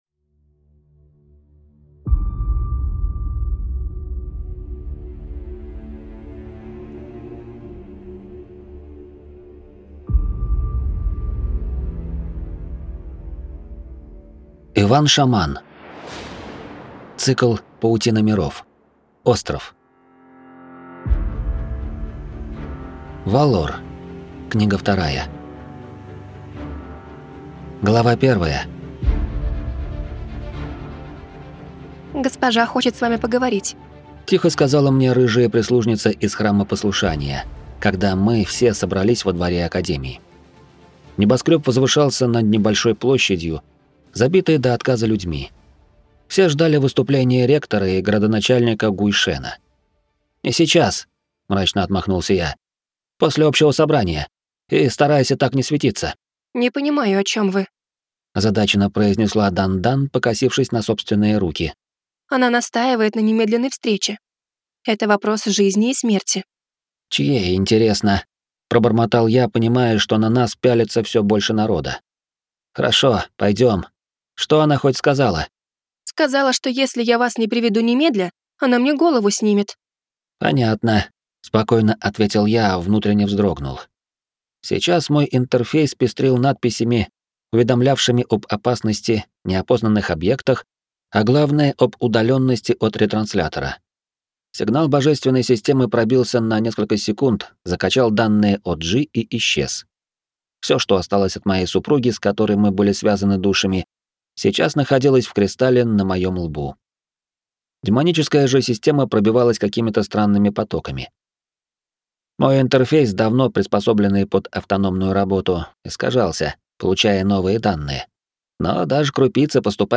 Аудиокнига Валор 2 | Библиотека аудиокниг